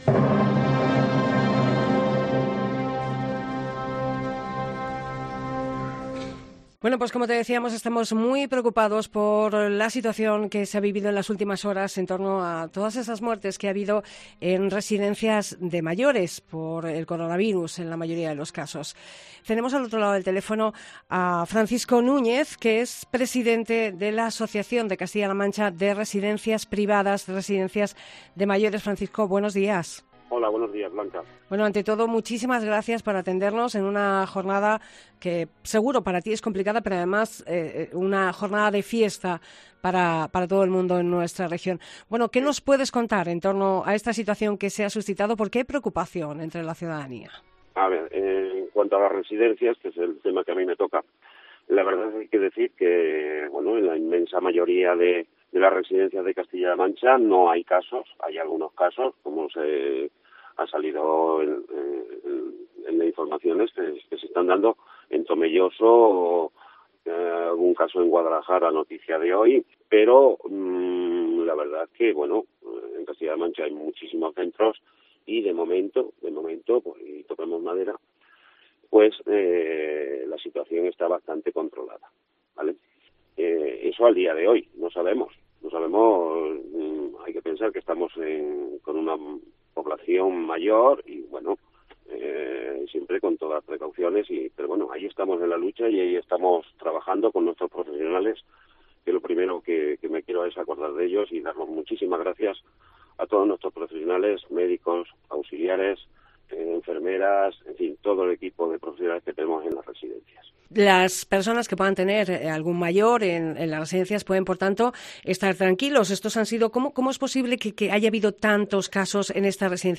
Entrevista ARTECAM